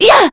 Scarlet-Vox_Attack2.wav